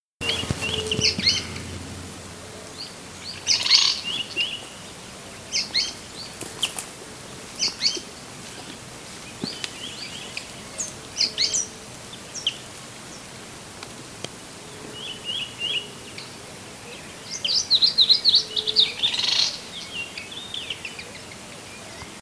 Slaty Elaenia (Elaenia strepera)
canto en 0:03 y 0:19
Location or protected area: Reserva Natural Vaquerías
Condition: Wild
Certainty: Photographed, Recorded vocal